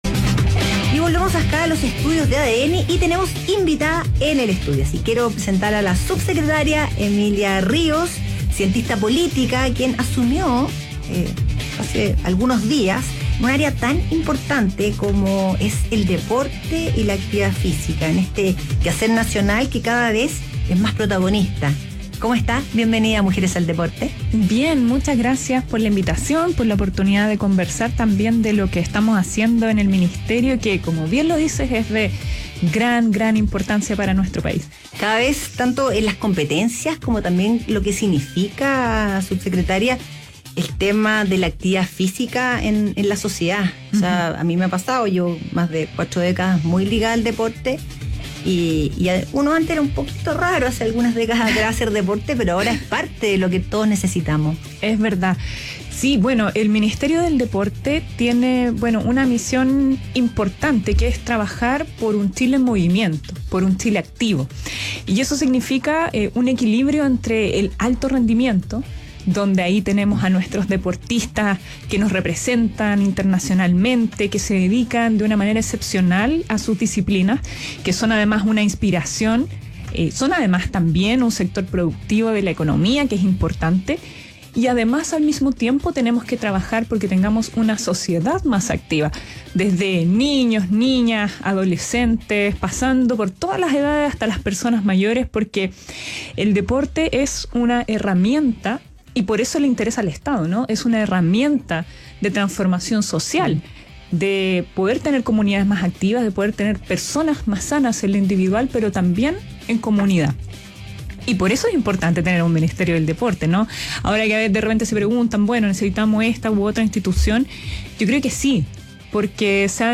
En conversación con Mujeres al Deporte, la subsecretaria del Deporte afrontó los desafíos de cara al 2025 en la cartera.